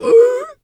seal_walrus_2_hurt_04.wav